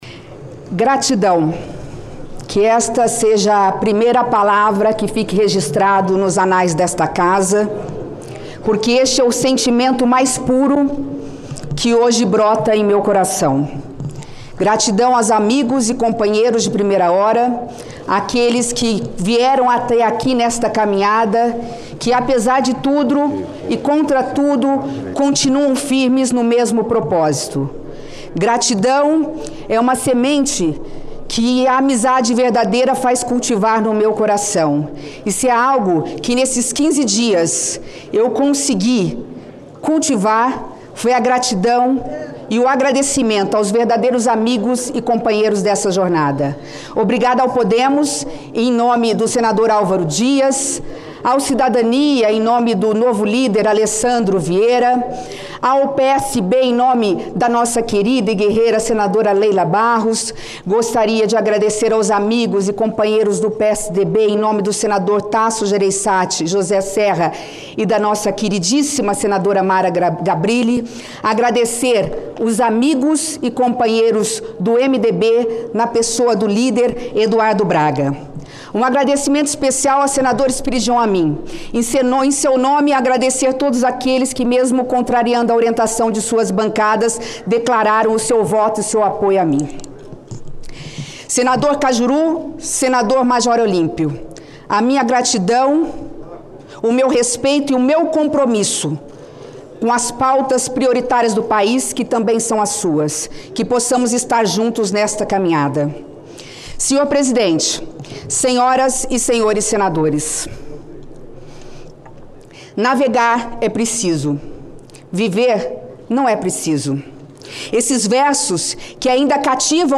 Ouça o áudio com o pronunciamento de Simone.